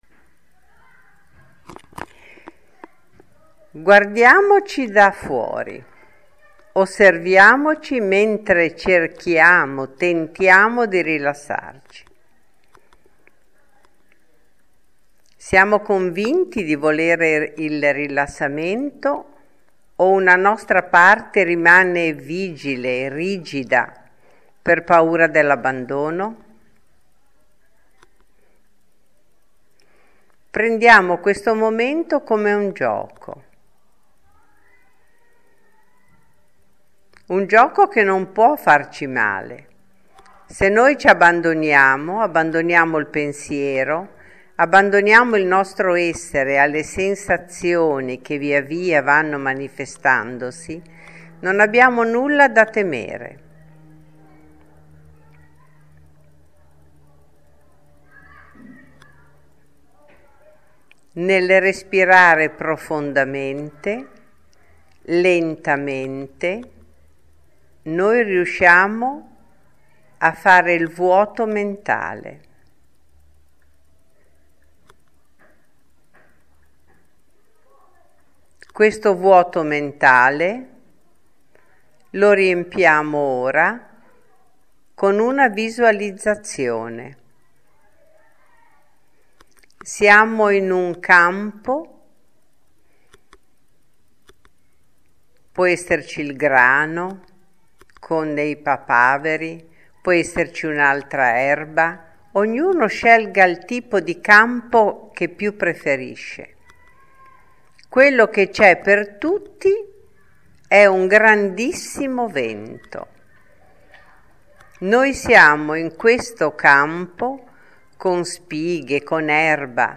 Il Vento – meditazione
Il-Vento-meditazione-.mp3